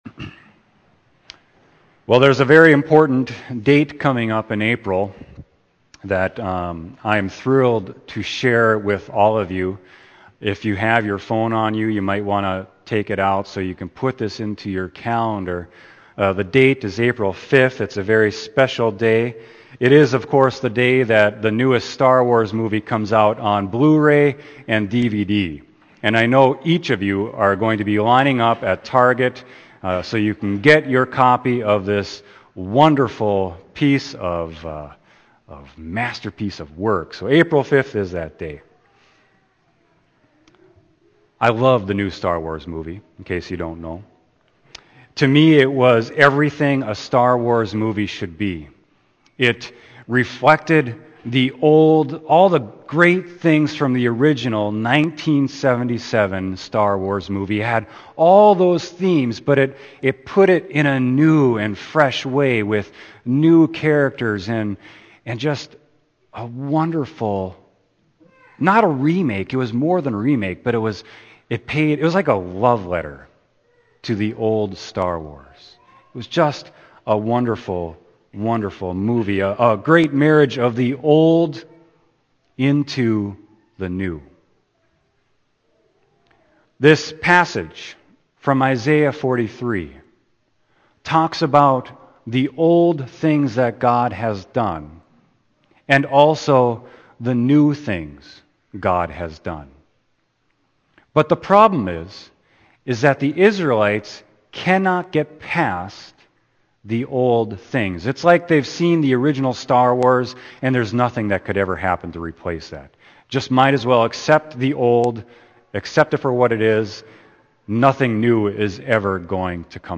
Sermon: Isaiah 43.16-21